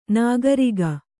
♪ nāgariga